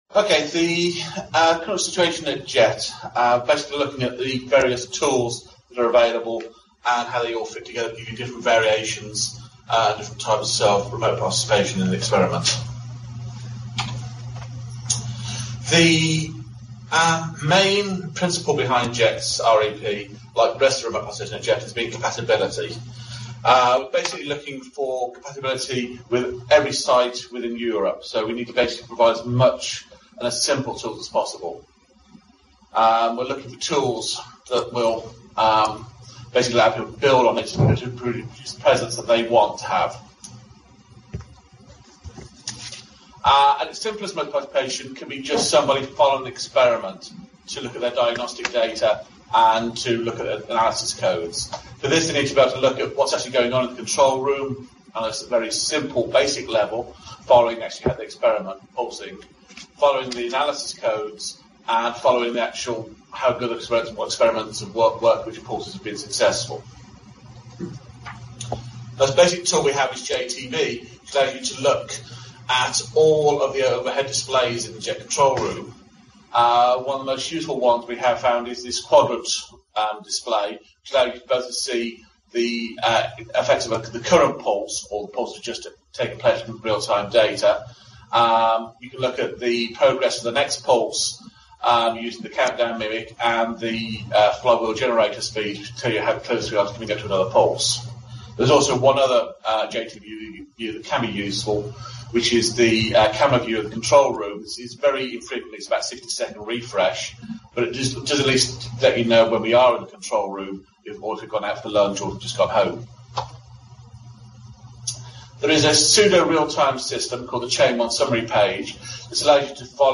EFDA- Remote Participation Contacts Workshop, Riga, Latvia Kategóriák Alkalmazott informatika